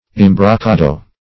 Search Result for " imbrocado" : The Collaborative International Dictionary of English v.0.48: Imbrocado \Im`bro*ca"do\, n.; pl.